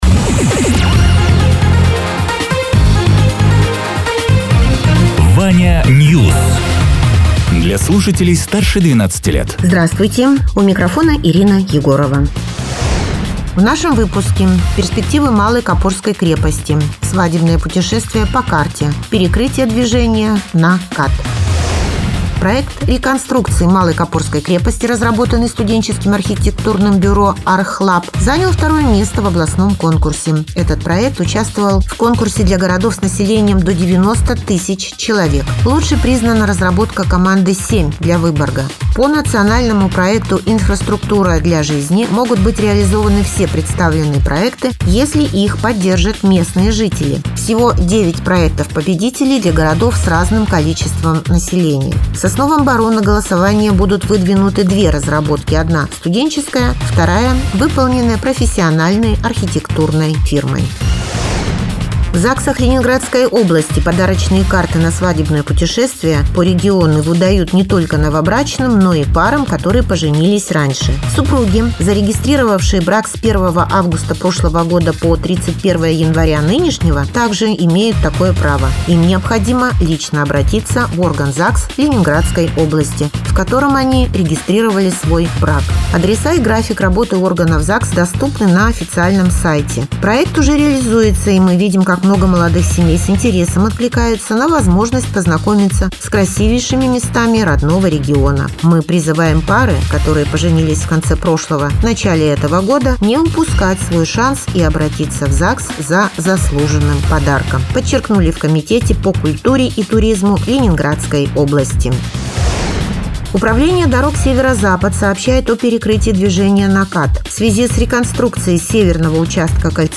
Радио ТЕРА 14.04.2026_12.00_Новости_Соснового_Бора